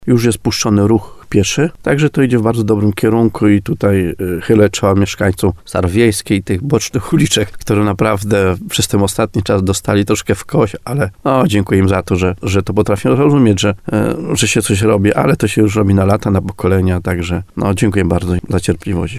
Mieszkańcy gminy Chełmiec odczują różnicę [ROZMOWA]
Informację potwierdza wójt gminy Chełmiec Stanisław Kuzak.
Jak mówił w programie Słowo za Słowo na antenie radia RDN Nowy Sącz, zakończenie remontu najważniejszej drogi, łączącej miasto Nowy Sącz z centrum Chełmca, wpłynie znacząco na upłynnienie ruchu w całej okolicy.